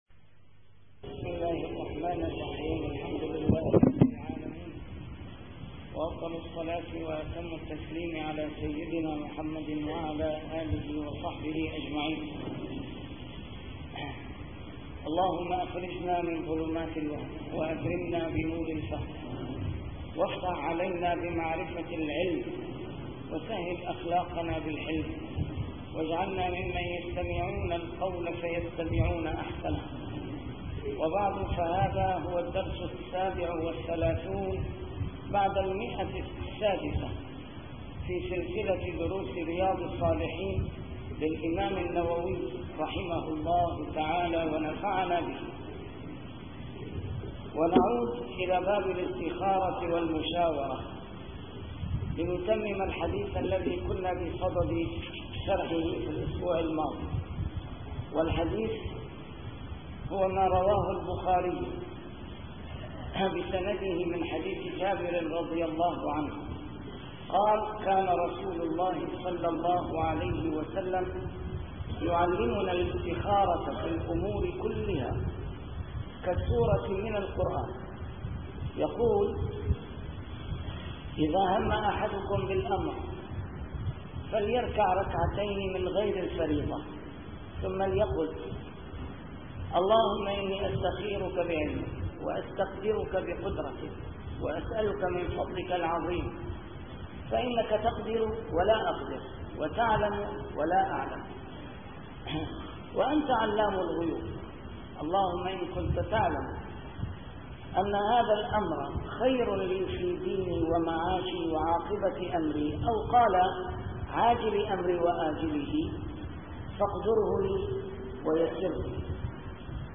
شرح كتاب رياض الصالحين - A MARTYR SCHOLAR: IMAM MUHAMMAD SAEED RAMADAN AL-BOUTI - الدروس العلمية - علوم الحديث الشريف - 637- شرح رياض الصالحين: الاستخارة والمشاورة